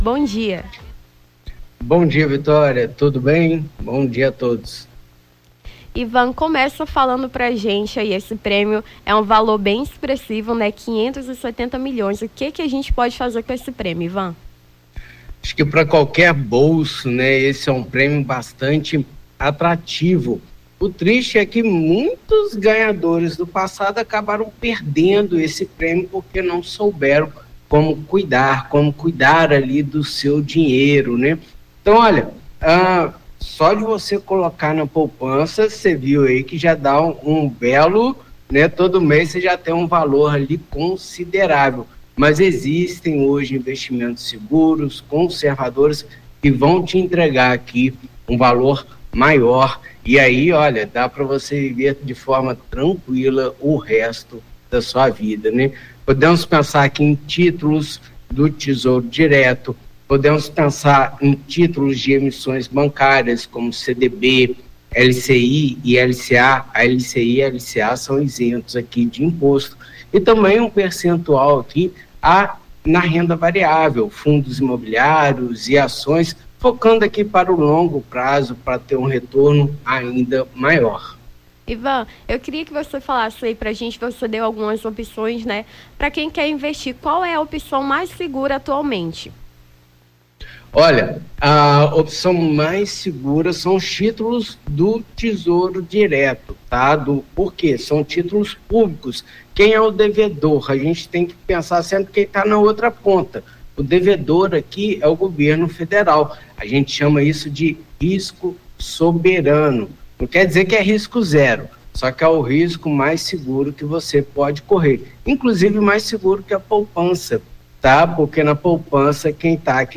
Nome do Artista - CENSURA - ENTREVISTA (MEGA DA VIRADA) 29-12-23.mp3